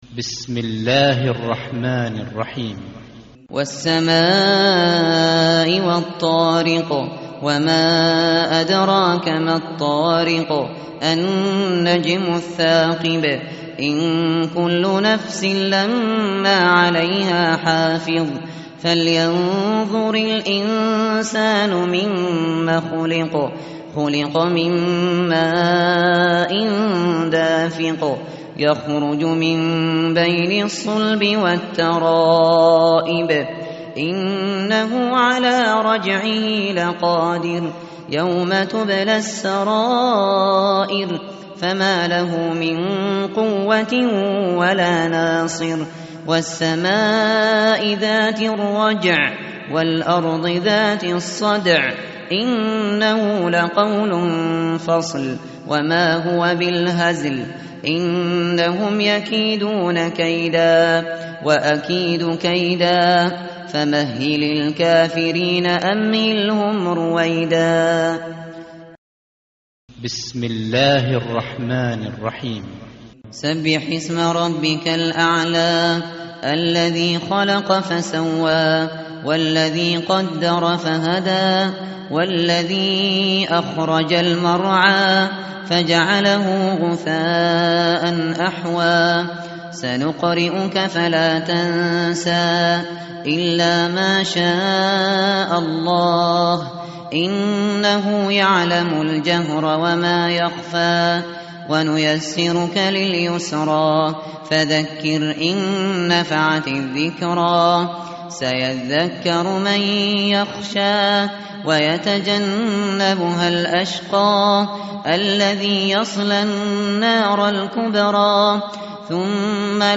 tartil_shateri_page_591.mp3